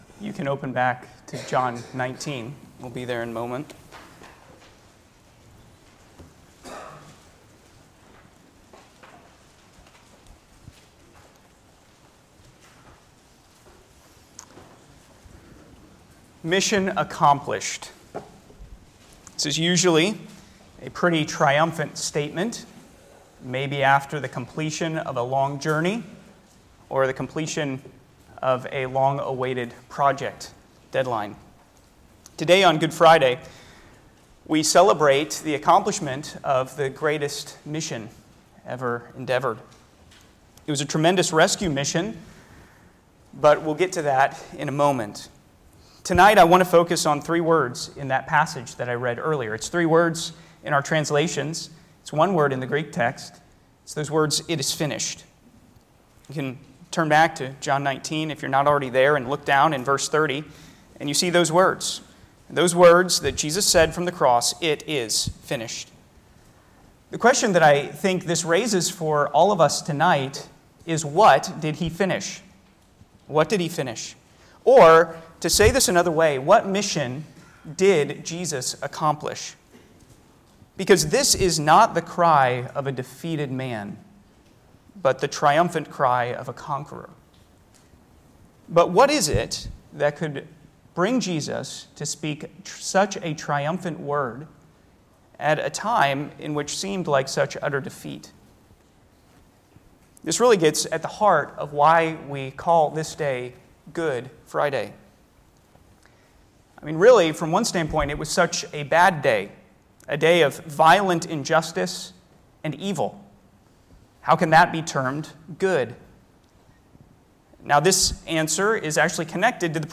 Holiday Service
Easter-4.18.25-Sermon.mp3